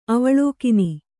♪ avaḷōkini